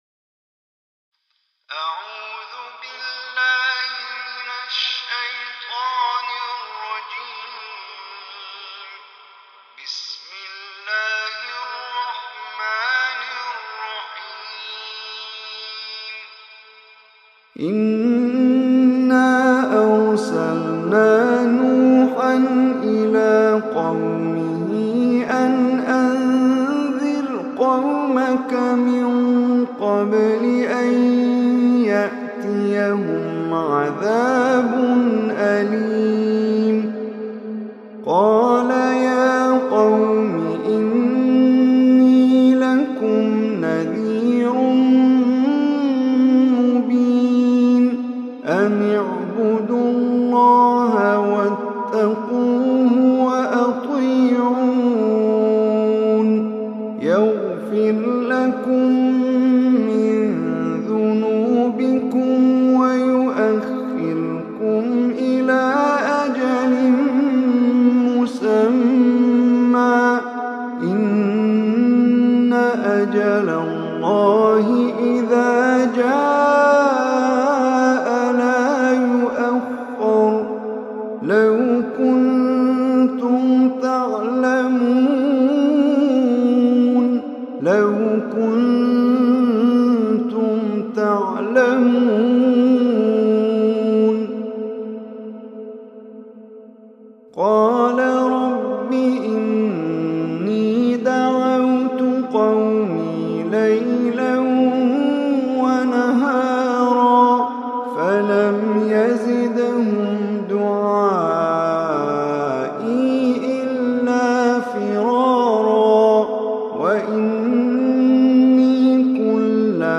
Surah Nuh Online Recitation by Omar Hisham Arabi
Surah Nuh, is 71 surah of Holy Quran. Listen online mp3 tilawat / recitation of Surah Nooh in the beautiful voice of Omar Hisham Al Arabi.